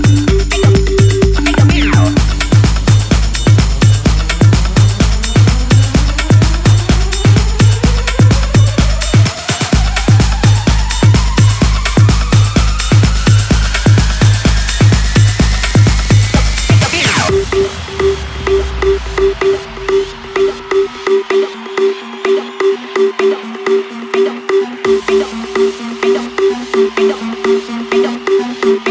iphone ringtones